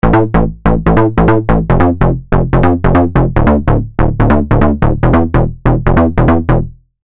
Если вы сделали все правильно, то в случае с "конструкцией1" у вас должен получиться такой звук(
after_using_my_preset.mp3